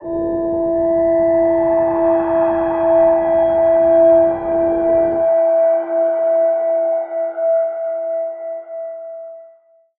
G_Crystal-E5-f.wav